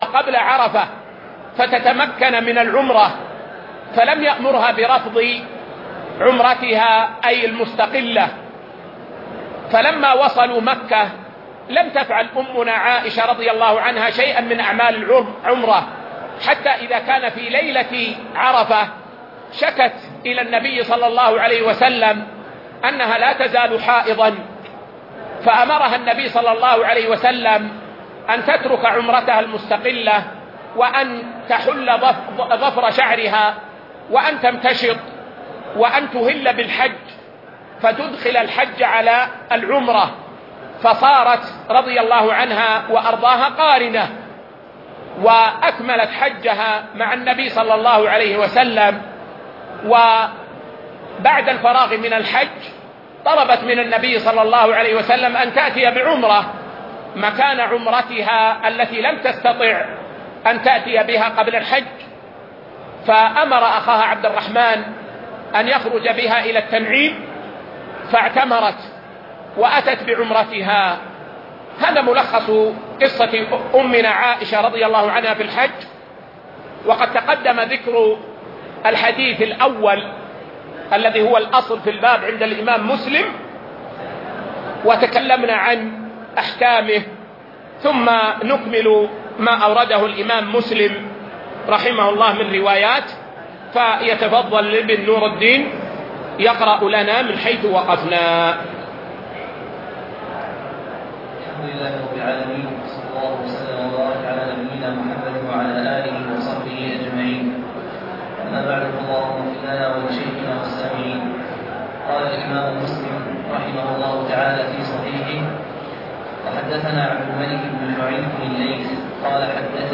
شرح صحيح مسلم الدرس 16